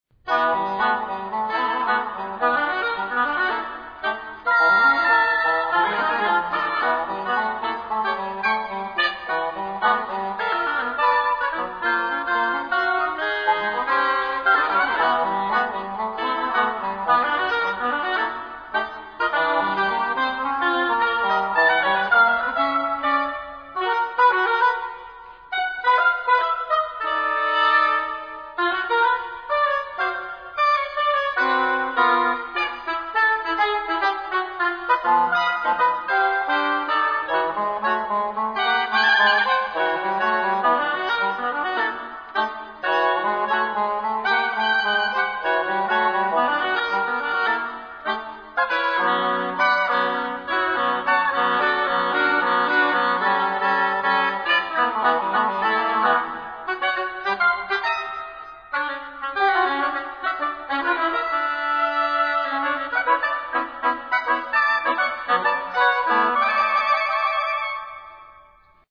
Beautifully matched in sound, intonation, and technique